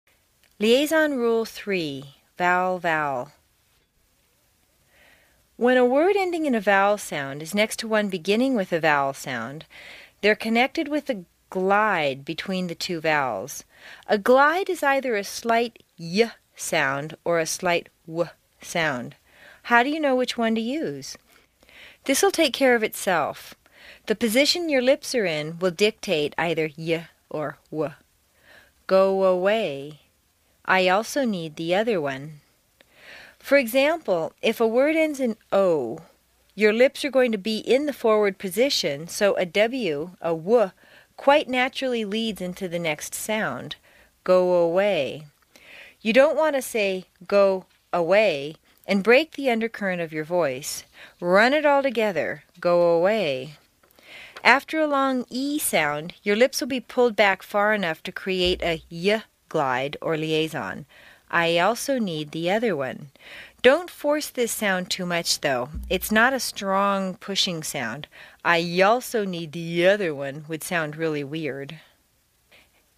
在线英语听力室美式英语正音训练第57期:连音规则的听力文件下载,详细解析美式语音语调，讲解美式发音的阶梯性语调训练方法，全方位了解美式发音的技巧与方法，练就一口纯正的美式发音！